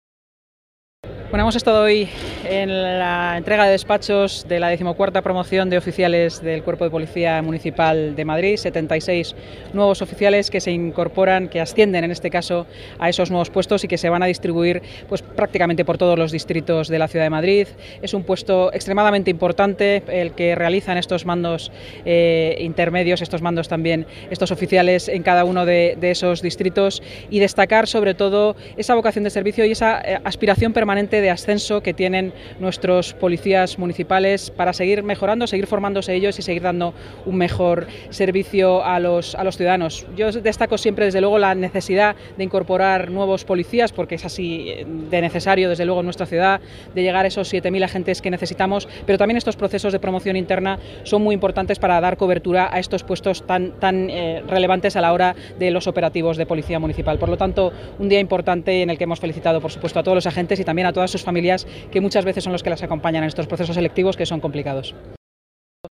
Son palabras, esta mañana, de la delegada de Seguridad y Emergencias y portavoz del Ayuntamiento de Madrid, Inmaculada Sanz, durante el acto de nombramientos de los 76 nuevos oficiales de la XIV promoción de la Policía Munic